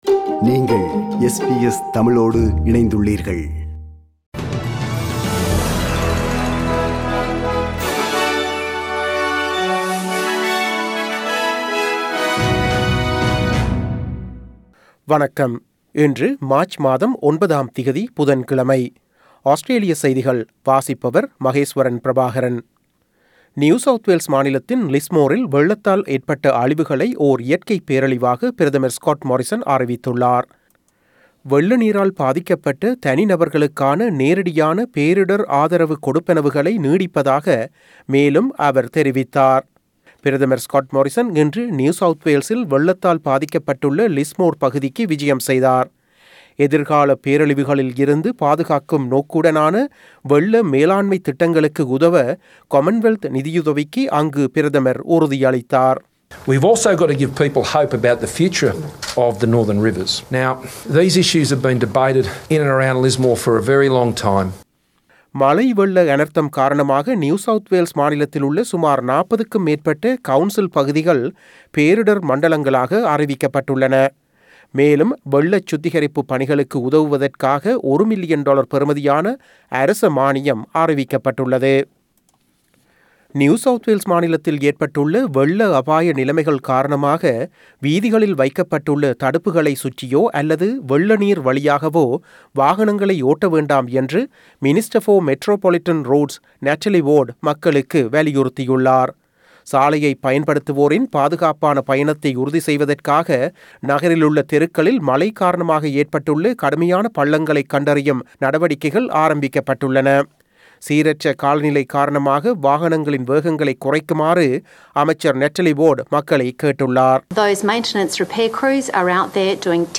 Source: SBS Tamil/AP SBS Tamil View Podcast Series Follow and Subscribe Apple Podcasts YouTube Spotify Download (12.02MB) Download the SBS Audio app Available on iOS and Android Australian news bulletin for Wednesday 09 March 2022.